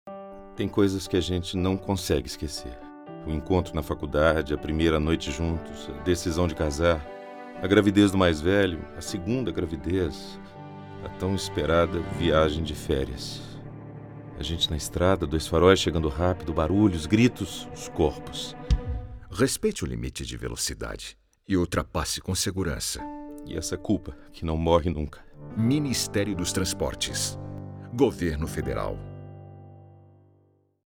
spot-min-transp-culpa-9-mp3